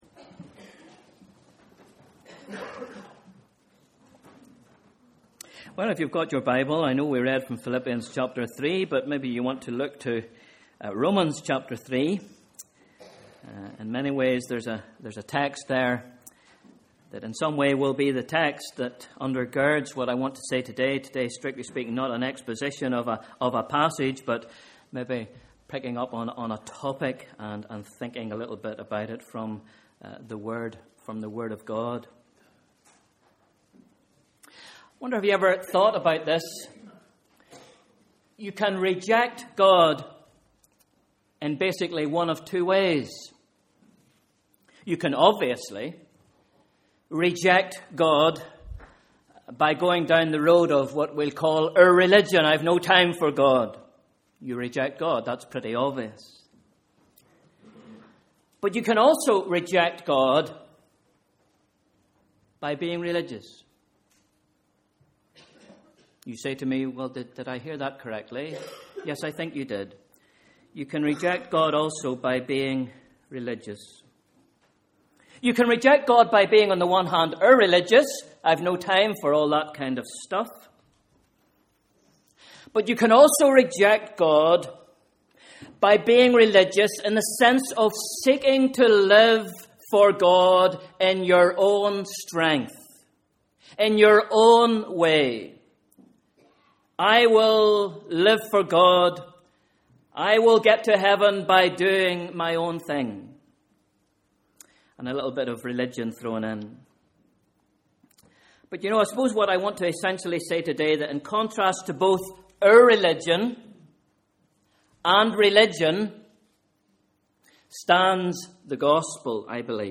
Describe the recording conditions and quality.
Morning Service: Sunday 14th April